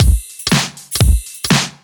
OTG_Kit7_Wonk_130a.wav